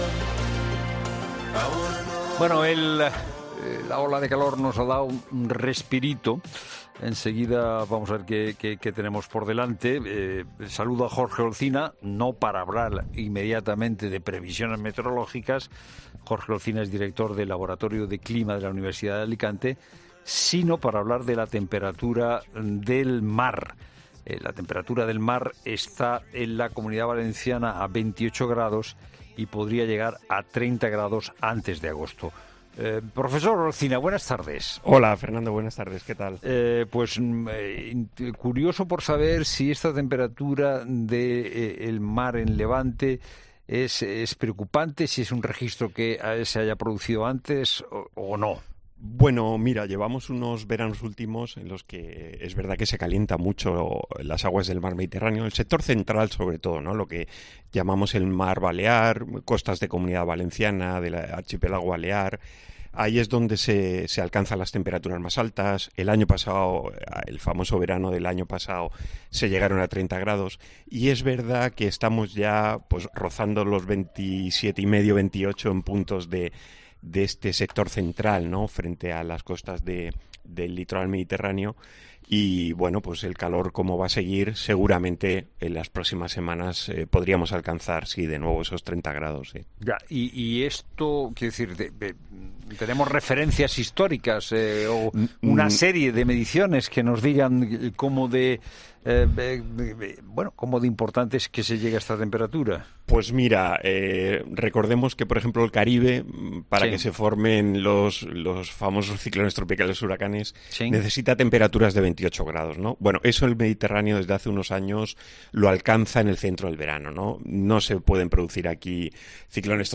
Escucha la explicación completa del meteorólogo en el siguiente audio.